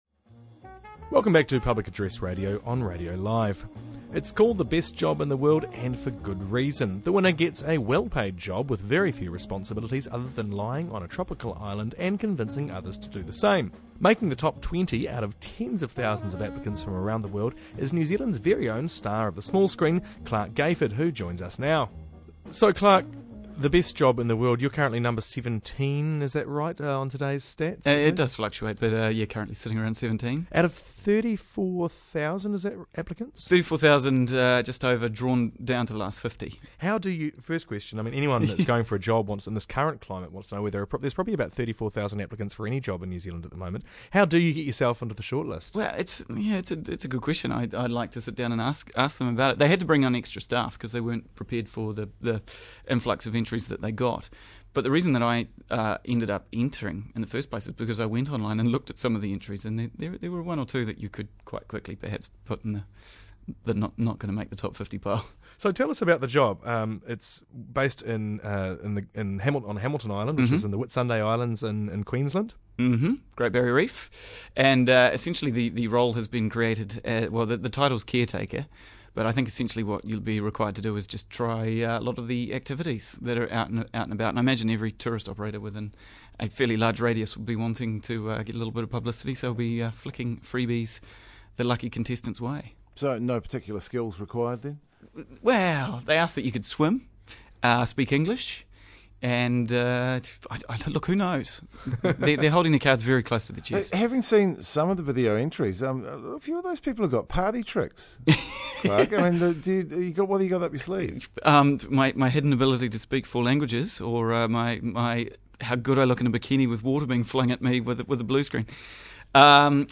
talk to Clarke Gayford, who has somehow managed to get himself into the top 50 'short-list' out of 30,000+ applicants for the 'Best Job in the World' competition...